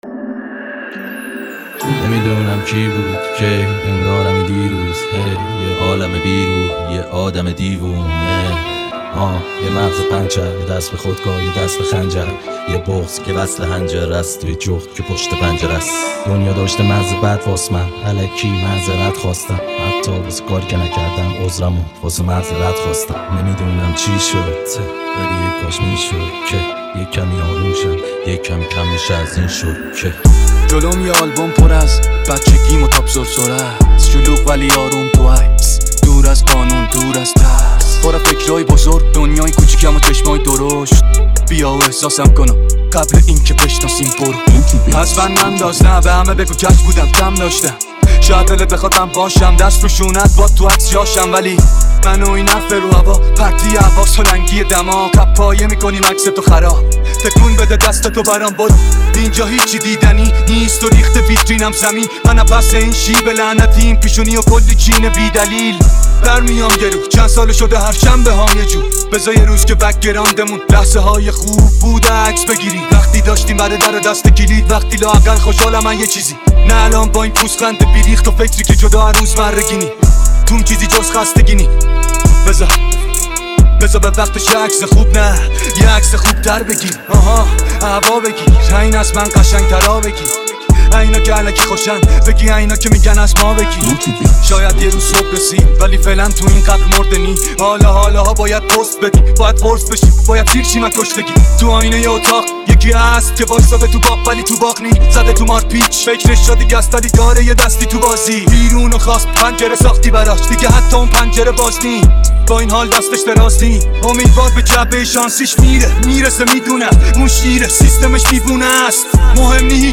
ریمیکس جدید رپی